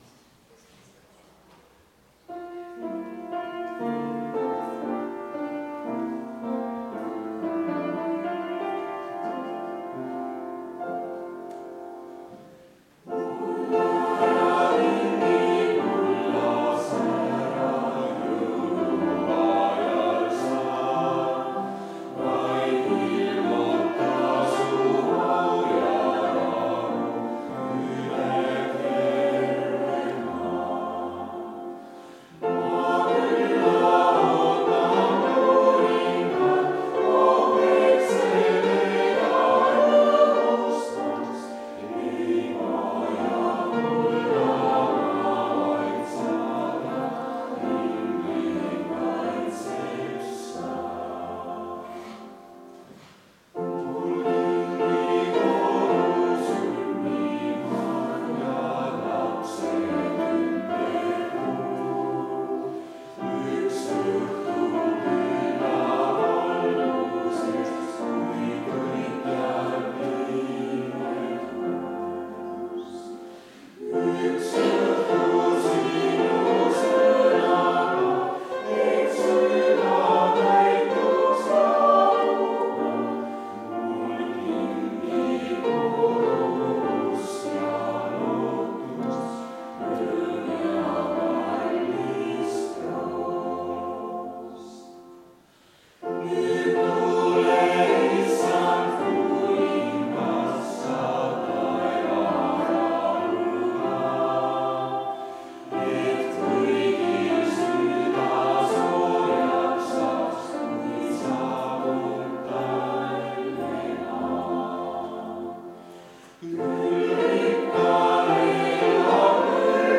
Tartu adventkoguduse 14.12.2024 hommikuse teenistuse jutluse helisalvestis.